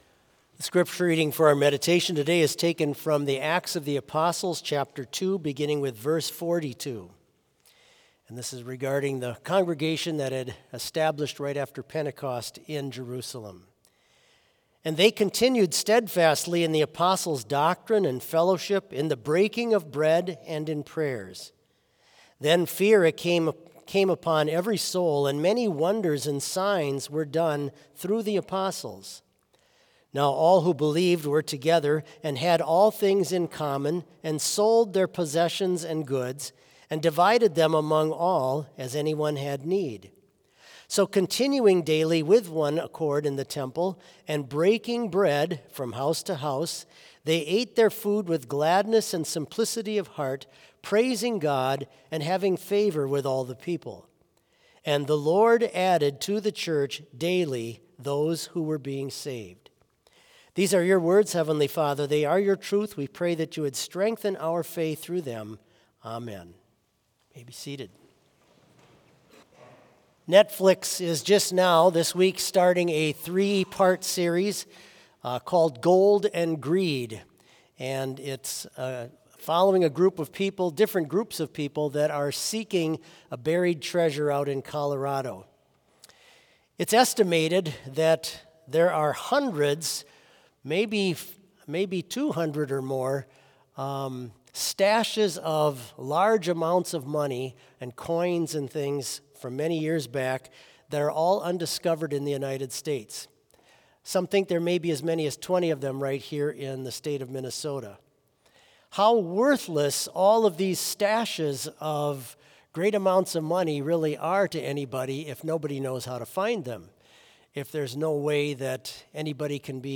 Complete service audio for Chapel - Wednesday, April 2, 2025